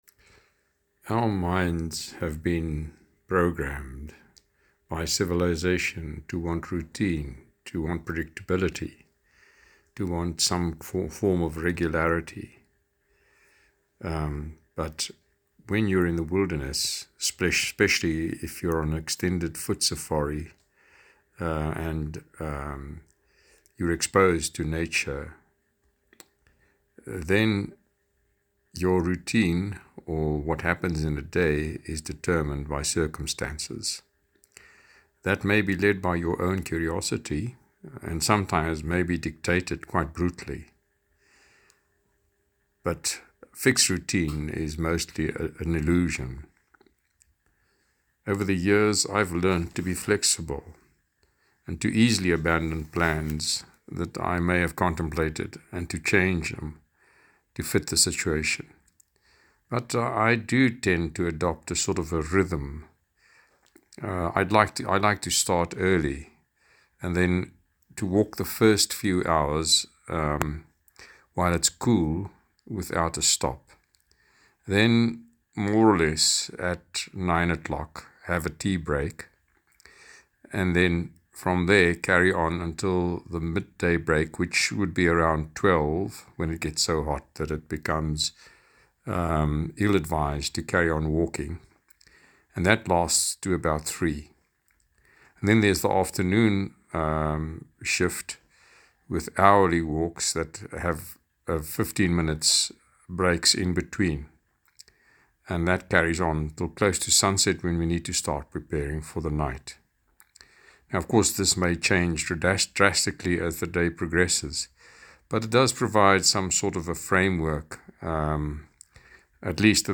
Here is a voice recording, or you can read through the text below.